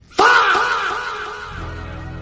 Sound Effects
Fahh But Louder